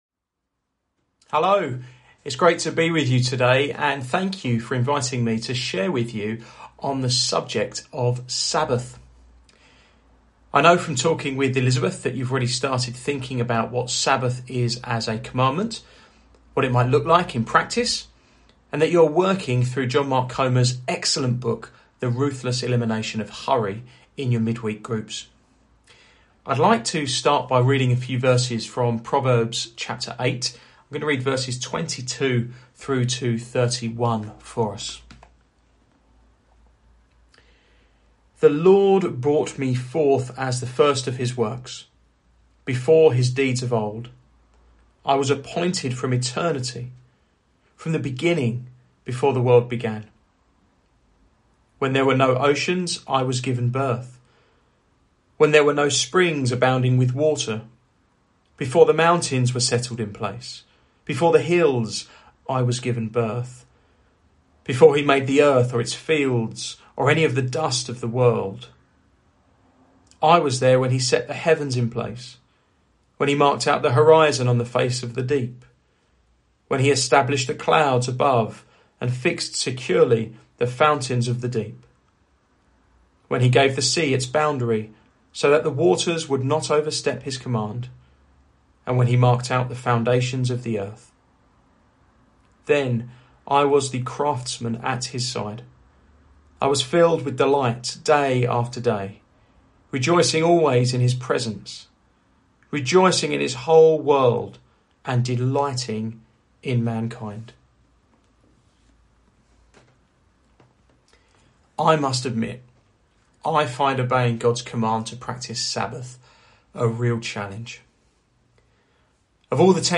Sabbath Service Type: Sunday Morning Preacher